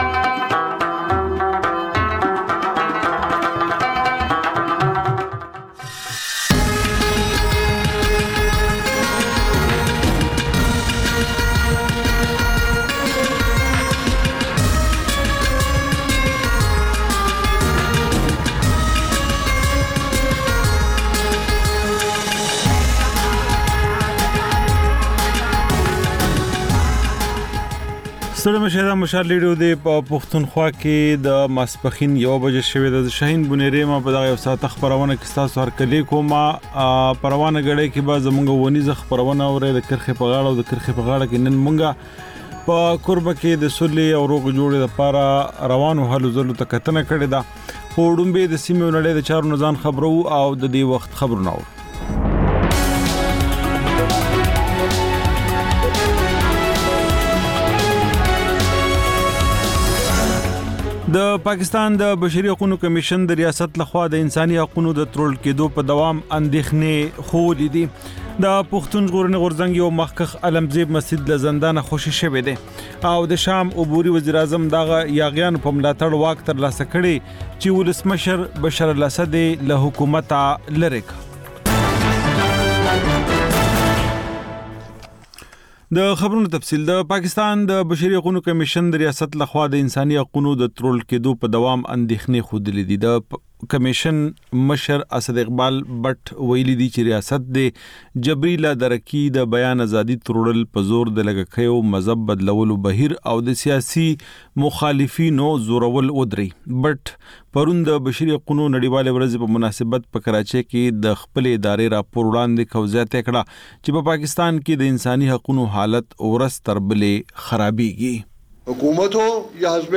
په دې خپرونه کې تر خبرونو وروسته بېلا بېل رپورټونه، شننې، مرکې خپرېږي.